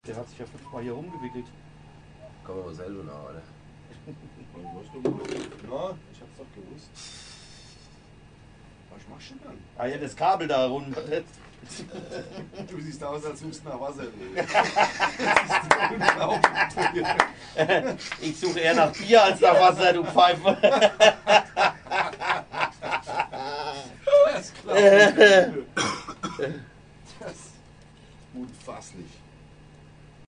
Diverse Kabel hatten sich um den Standfuß gewickelt. Um sich nicht bücken zu müssen, versuchte unser bequemer Bassist aufrecht stehend durch geschicktes Kreisen des Stativs die Kabel zu lösen !